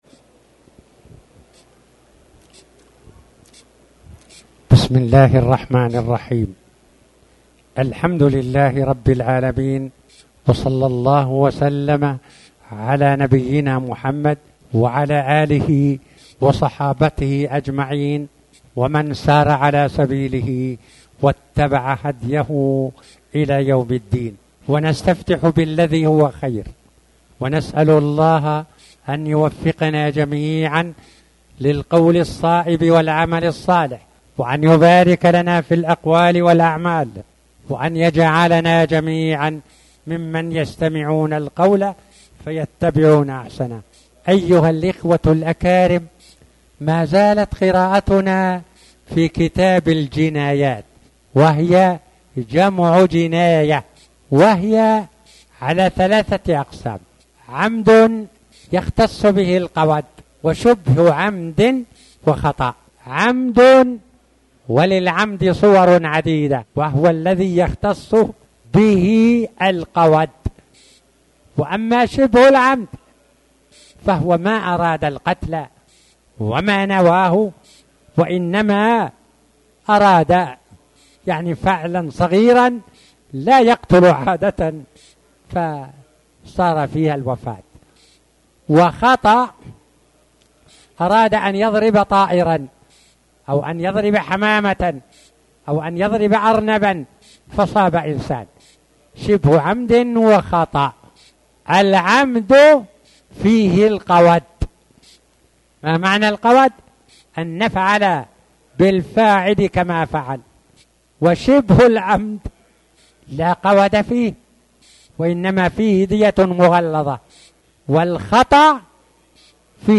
تاريخ النشر ٢٩ رجب ١٤٣٩ هـ المكان: المسجد الحرام الشيخ